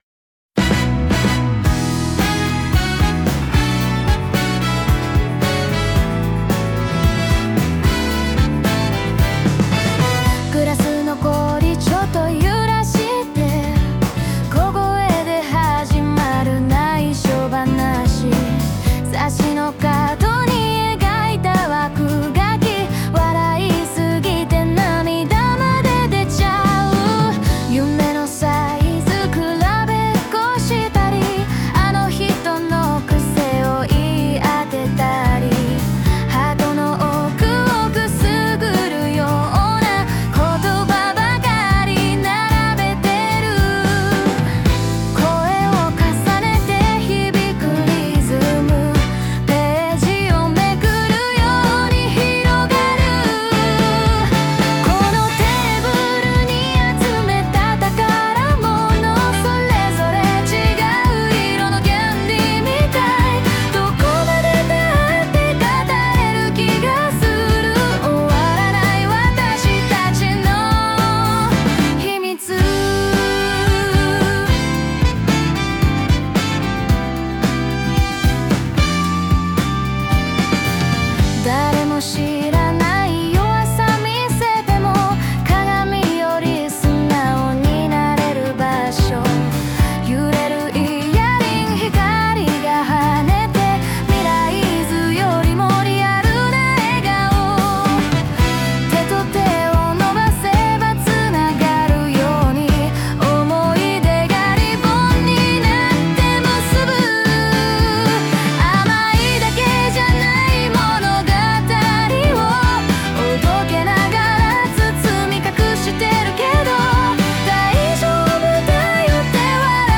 著作権フリーオリジナルBGMです。
女性ボーカル（邦楽・日本語）曲です。